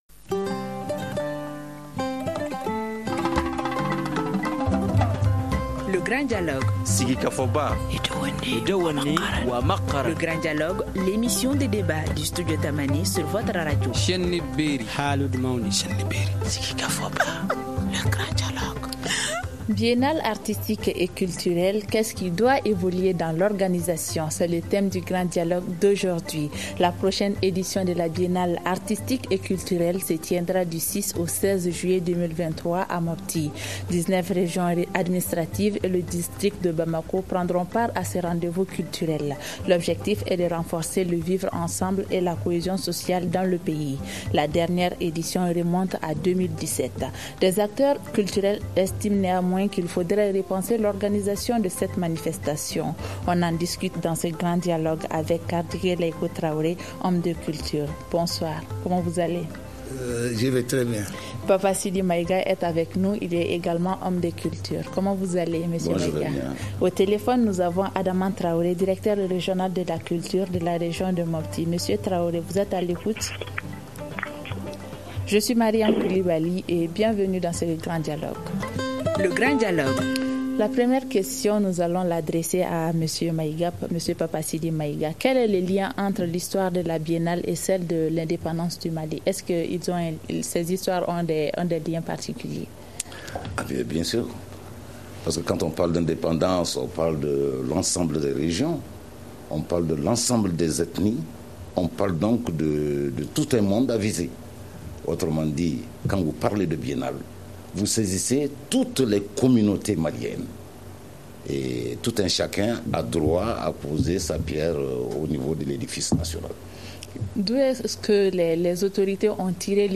Des acteurs culturels estiment qu’il faudrait repenser l’organisation de cette manifestation. On en discute dans ce Grand Dialogue.